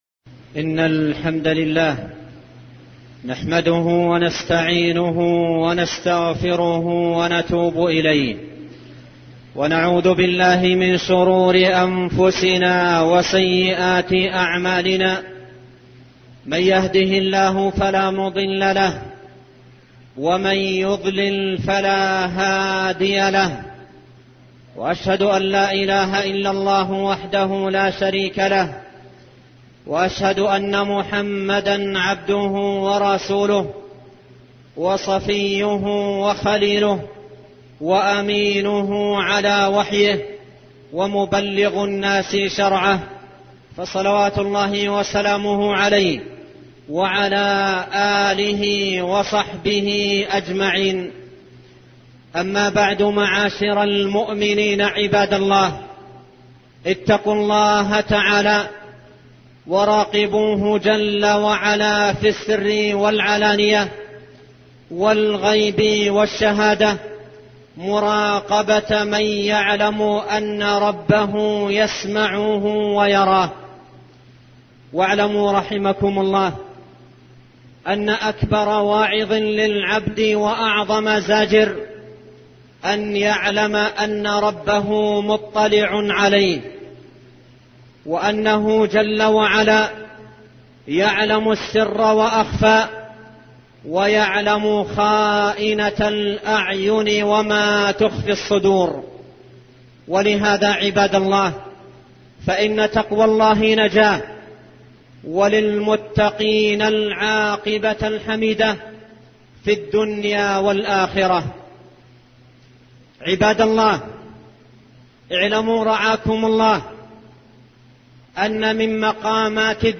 خطب مواعظ